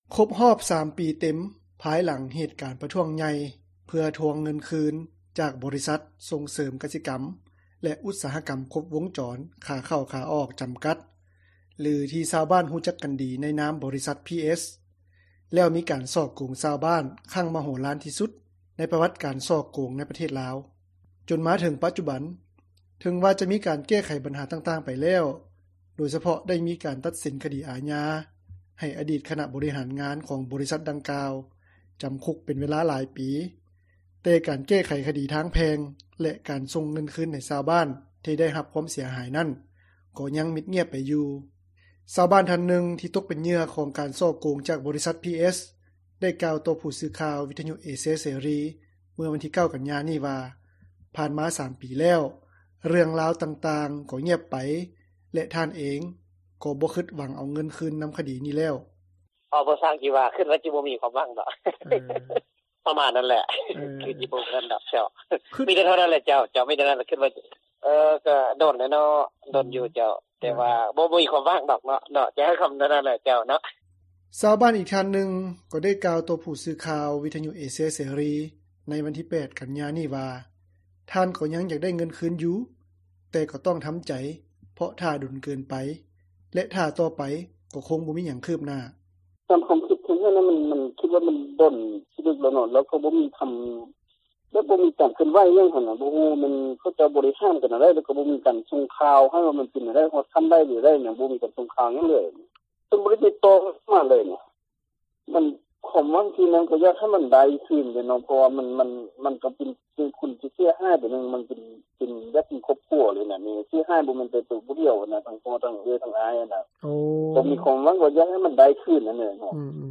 ຊາວບ້ານທ່ານນຶ່ງ ທີ່ຕົກເປັນເຫຍື່ອ ຂອງການສໍ້ໂກງຈາກບໍຣິສັດ PS ໄດ້ກ່າວຕໍ່ຜູ້ສື່ຂ່າວ ວິທຍຸເອເຊັຽເສຣີ ເມື່ອວັນທີ 9 ກັນຍາ ນີ້ວ່າ ຜ່ານມາ 3 ປີ ແລ້ວ ເຣື່ອງລາວຕ່າງໆກໍງຽບໄປ ແລະ ທ່ານເອງ ກໍບໍ່ຄຶດຫວັງເອົາເງິນຄືນນຳຄດີນີ້ແລ້ວ.